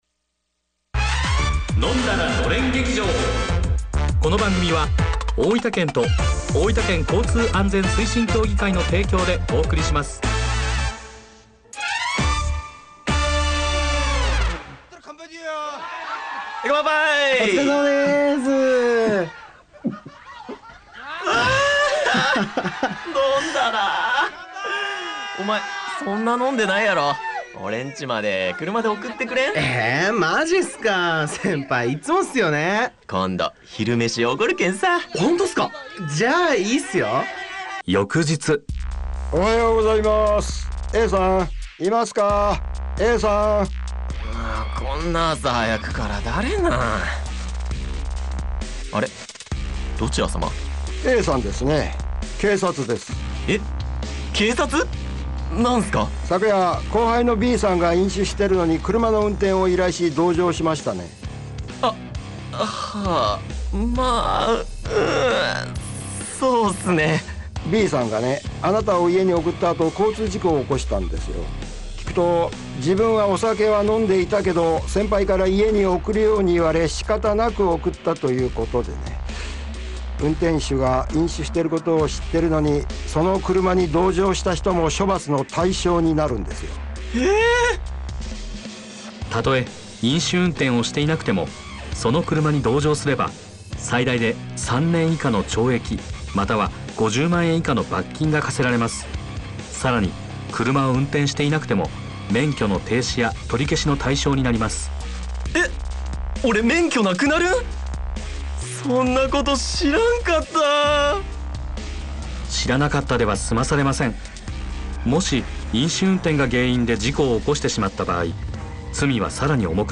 ラジオドラマ「飲んだらのれん劇場」をFM大分にて放送しました。